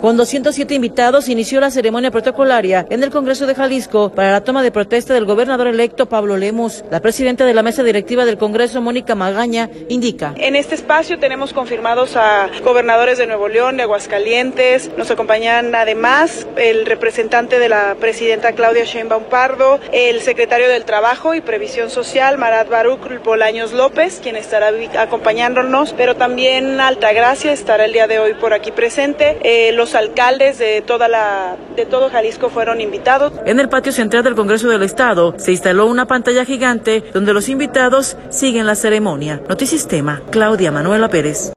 Con 207 invitados, inició la ceremonia protocolaria en el Congreso de Jalisco para la toma de protesta del gobernador electo, Pablo Lemus. La presidenta de la Mesa directiva del Congreso, Mónica Magaña, indica.